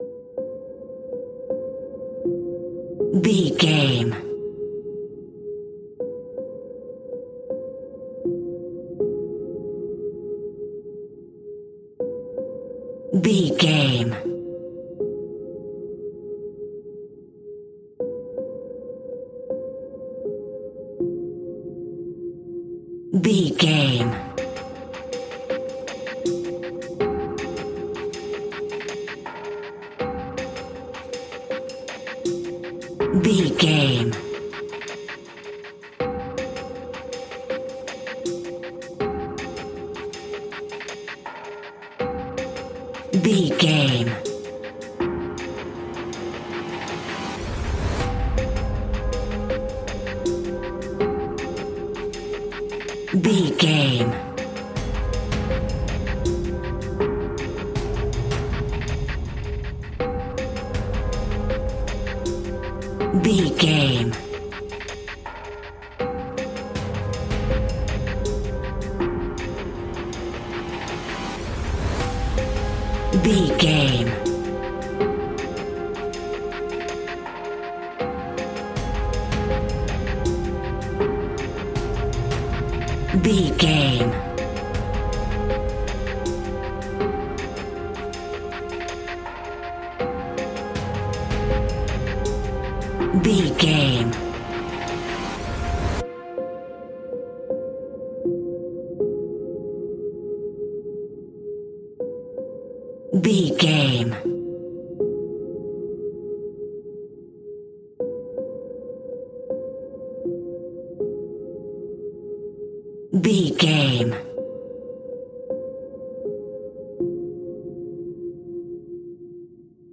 Scary Horror Hybrid Industrial Suspense Alt.
royalty free music
Aeolian/Minor
ominous
haunting
eerie
strings
drums
percussion
synthesiser
electric piano
instrumentals